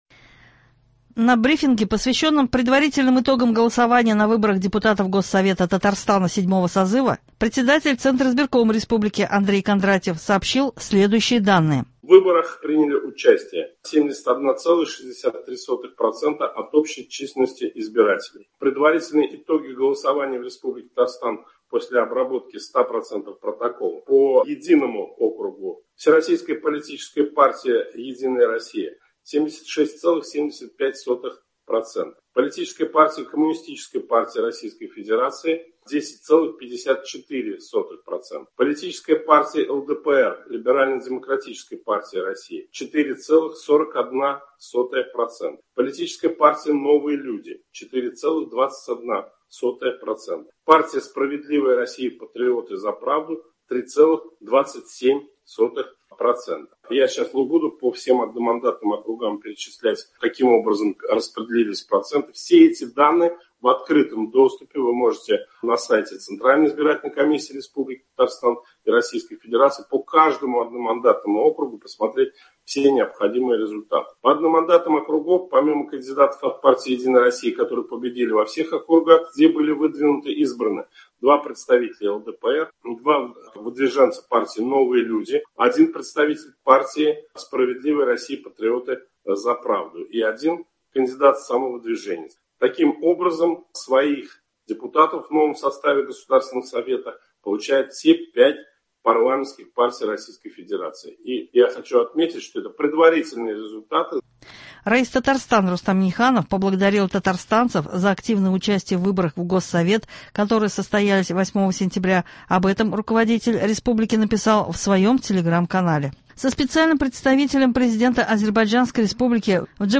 Новости (10.09.24)